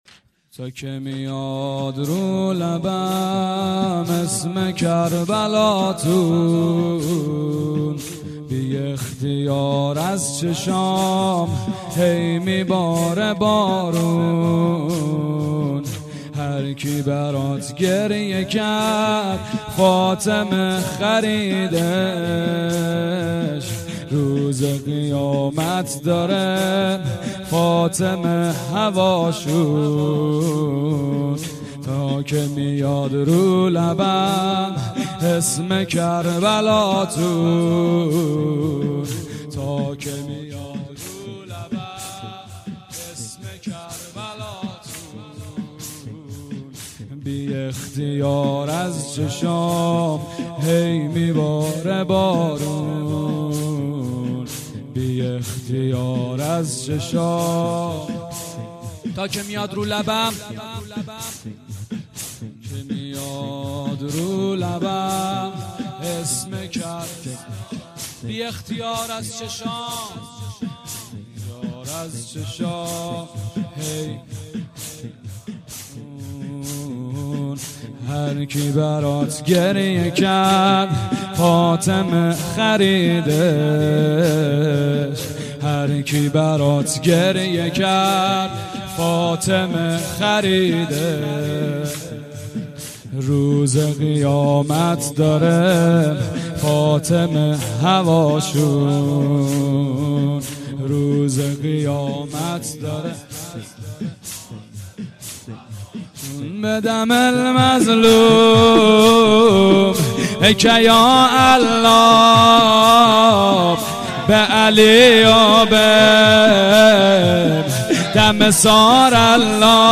مراسم هفتگی اشتراک برای ارسال نظر وارد شوید و یا ثبت نام کنید .